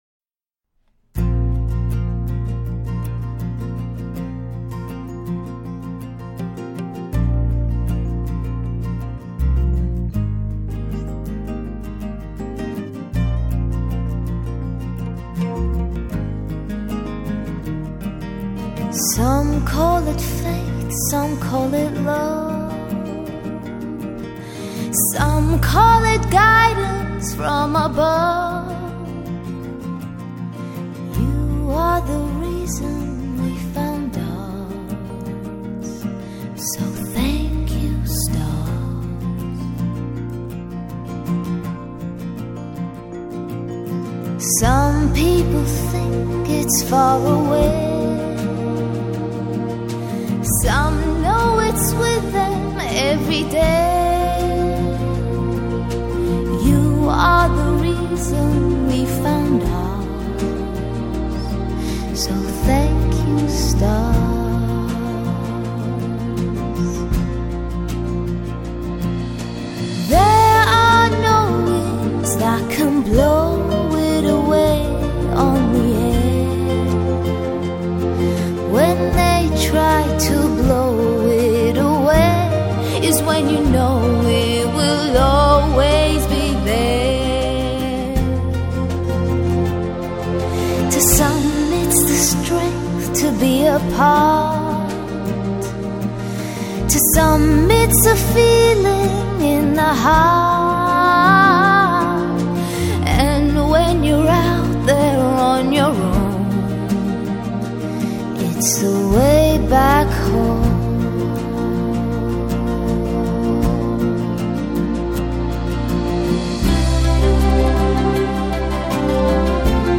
爵士小品